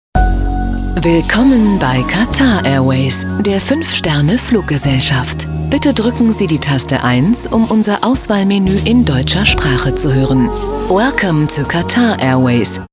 She is known for her versatile, warm and sovereign voice.
Sprechprobe: Industrie (Muttersprache):
german female voice over talent. Her extensive media experience includes leading positions in television as well as direction of radio commercials and event presentation